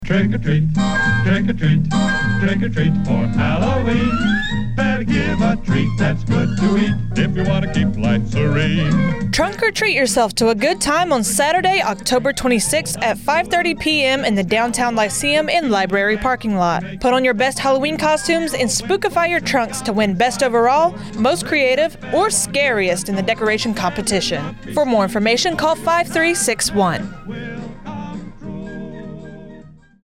A radio spot informing NSGB residents of the "Trunk or Treat" event hosted by MWR on Oct. 26, 2024.